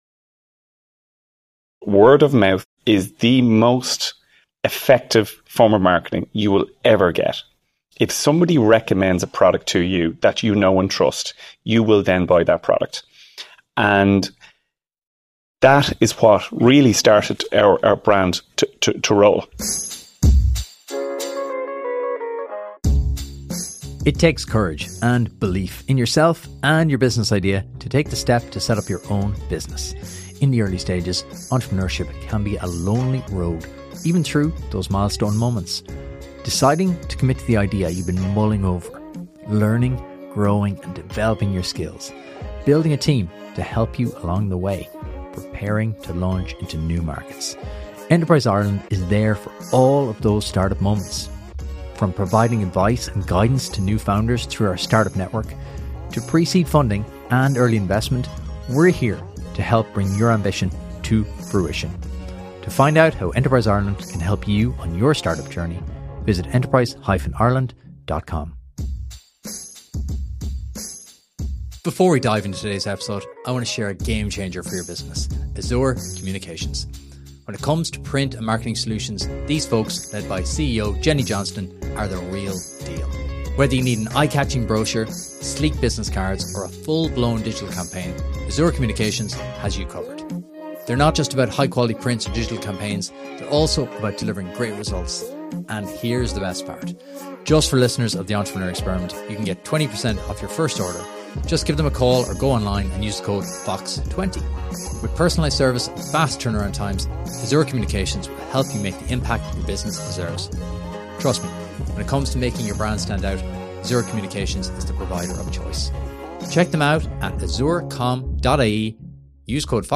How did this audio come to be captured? The Entrepreneur Experiment Live Special with The Local Enterprise Offices: Lessons from Ireland’s Top Founders.